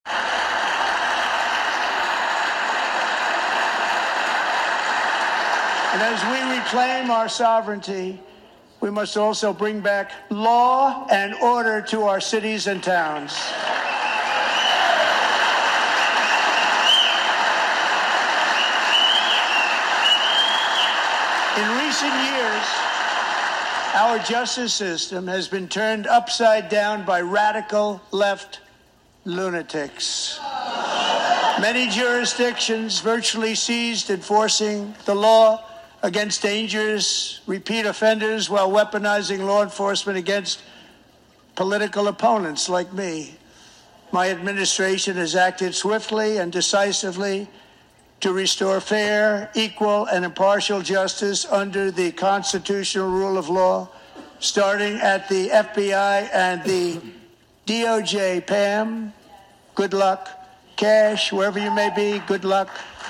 March 6, 2025, Donald Trump speaking before the joint session of Congress, supports what we do here!
Trump-Speech.ogg